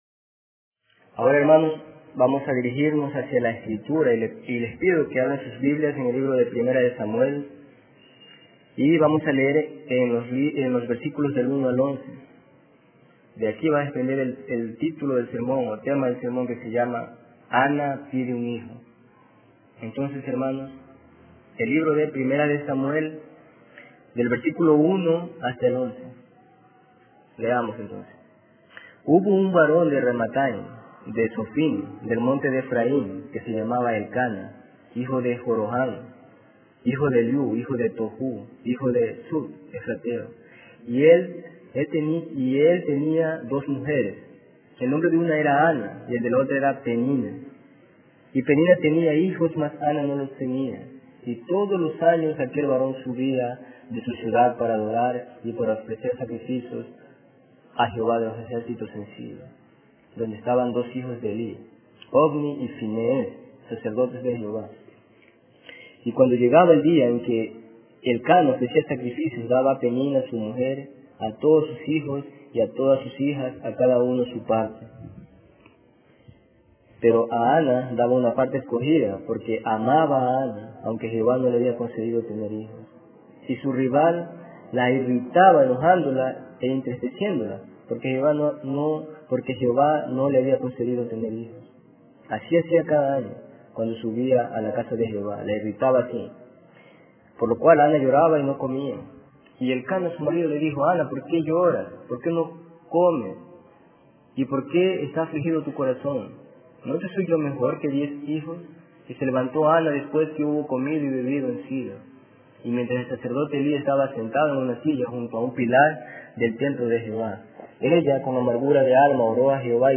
Tipo: Sermón